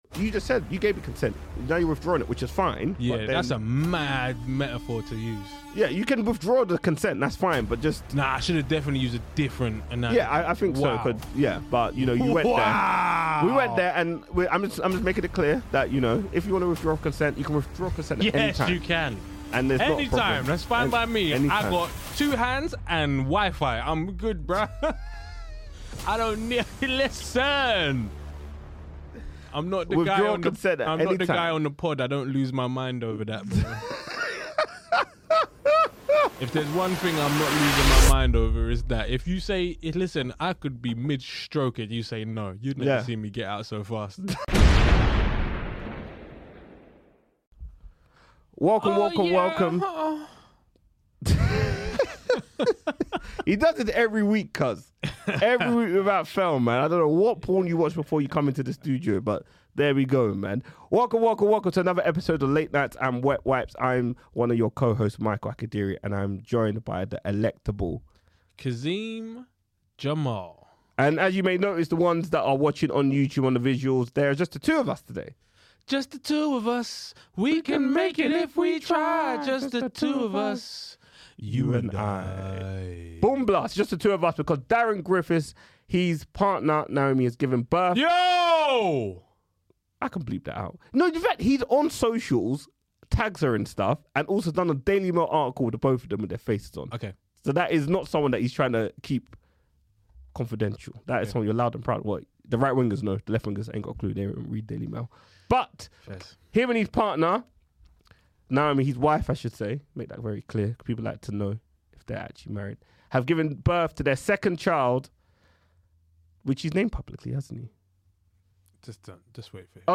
Only two of the guys this week